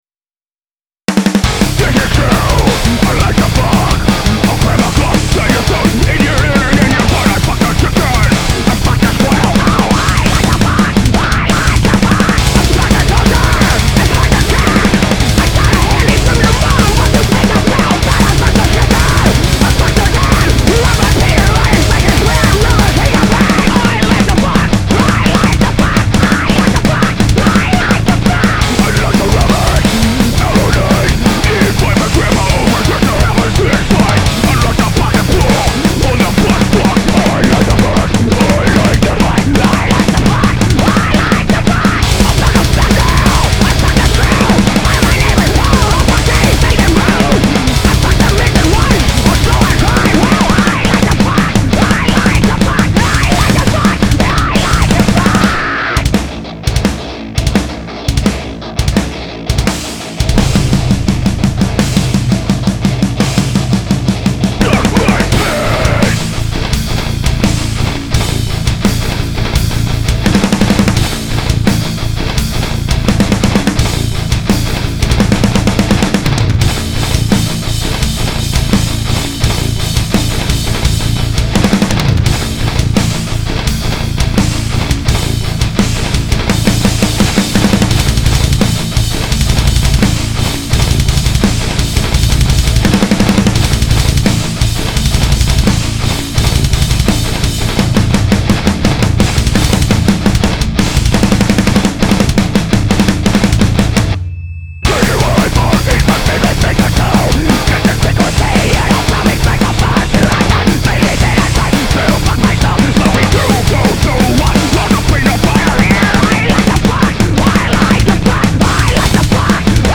------------------------ been working on a new guitar tone..... its basically, slightly crispy (the main), clean behind that (believe it or not) and the super fuzz track behind that..... think of it,level wise, as high (crispy)/ medium(clean)/ low(super fuzz) just to fill it out and make it more...
I LIKE TO FUCK. this tone works espescially well on the gallops.... at 1:05 please listen to it..... then... let the haters hate.